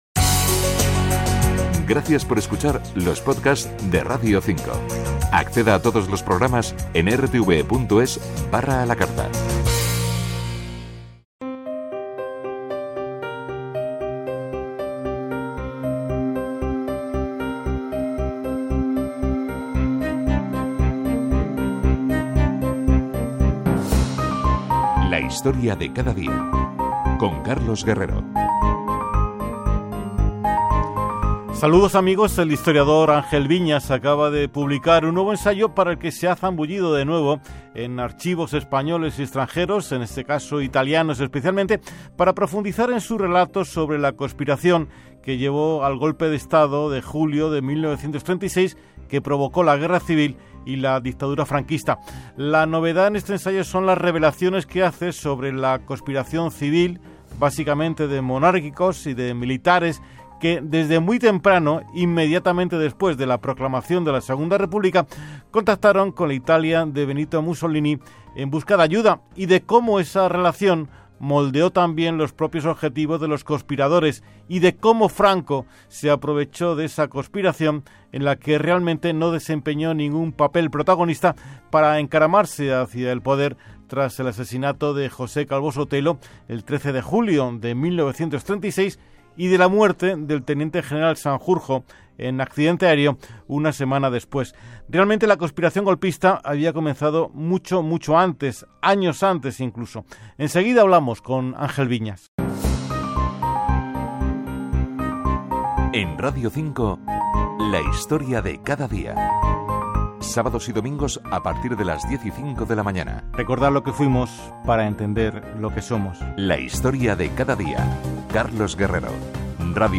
Relacionado Posted in: Angel Viñas , Entrevistas , Guerra Civil Española GCE , Memoria Histórica , Novedades , tertulias. entrevistas